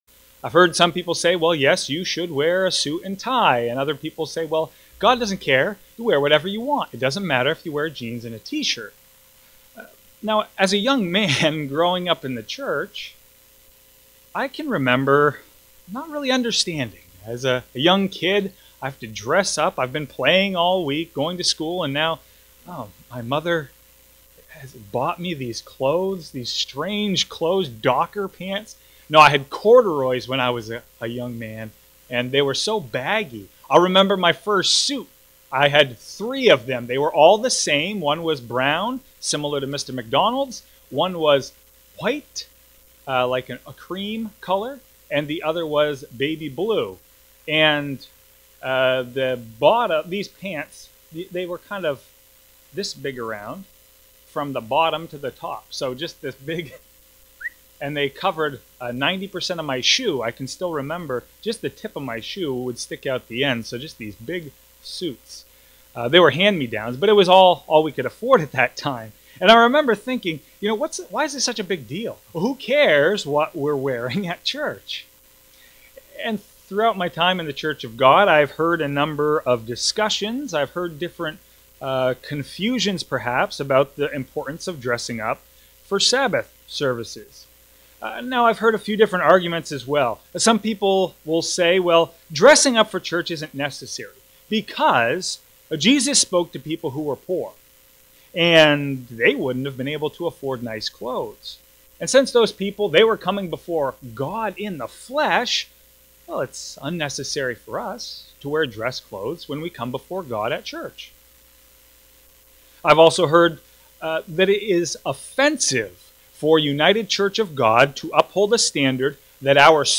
Sermons
Given in Bismarck, ND Fargo, ND